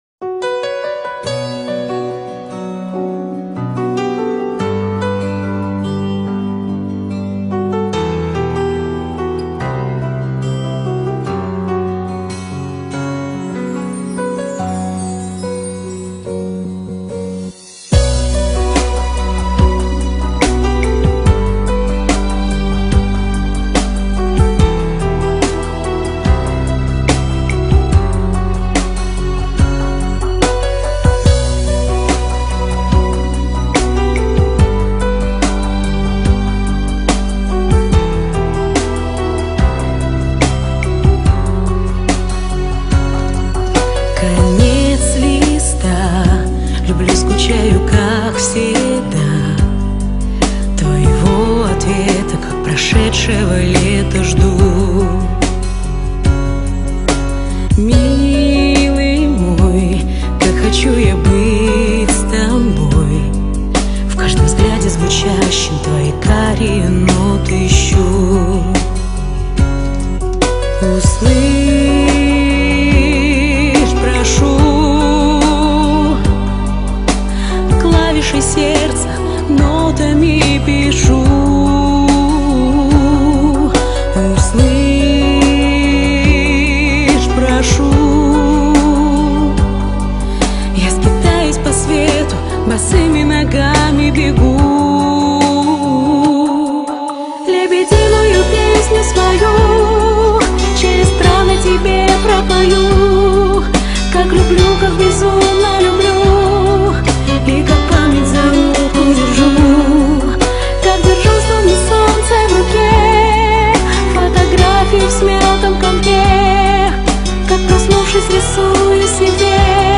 парень_с_девушкой_-_красиво_поют_о_любви
parenjq_s_devushkoq___krasivo_pojyt_o_ljybvi.mp3